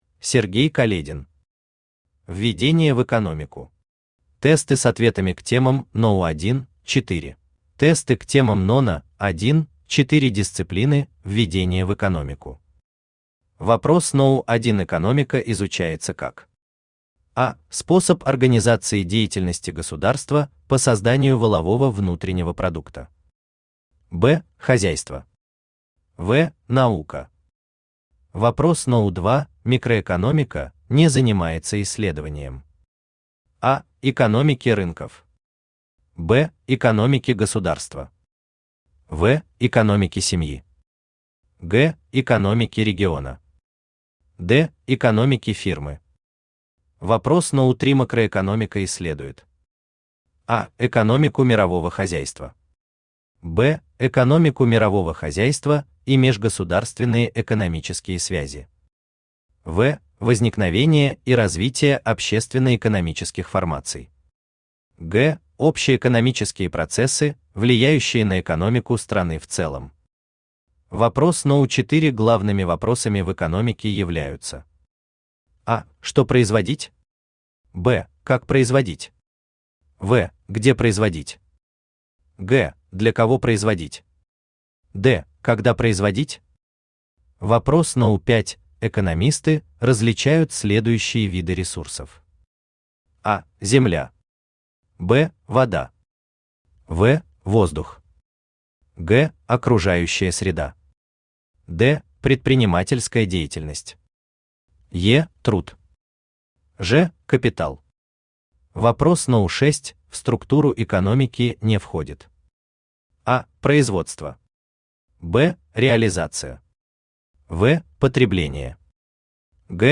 Аудиокнига Введение в экономику.
Aудиокнига Введение в экономику. Тесты с ответами к темам № 1–4 Автор Сергей Каледин Читает аудиокнигу Авточтец ЛитРес.